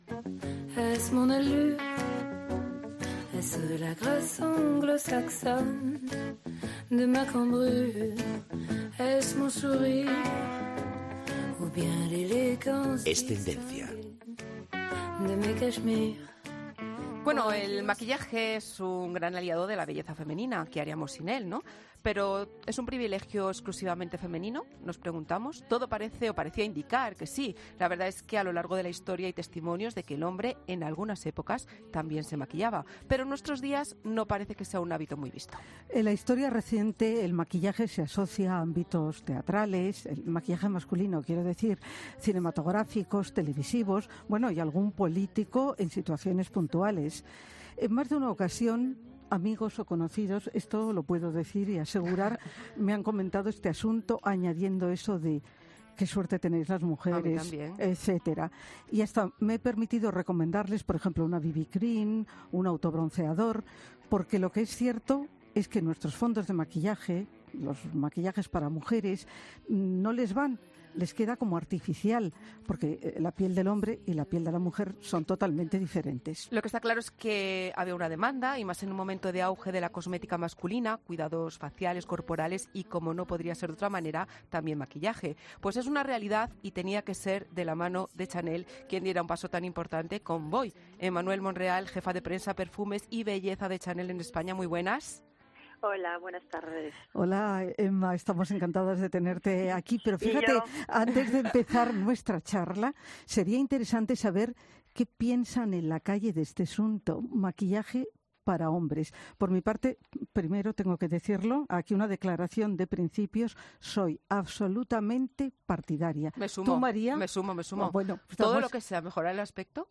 PINCHA AQUÍ PARA ESCUCHAR LA ENTREVISTA EN EL PODCAST 'COPE COOL' PINCHA AQUÍ PARA ESCUCHAR EL PODCAST COMPLETO 'COPE COOL' Los hombres buscan productos prácticos, cómodos y ligeros y detestan los brillos.